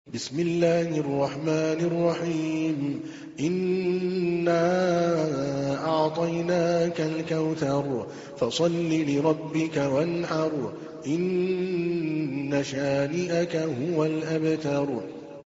تحميل : 108. سورة الكوثر / القارئ عادل الكلباني / القرآن الكريم / موقع يا حسين